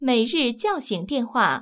ivr-daily_wakeup_call.wav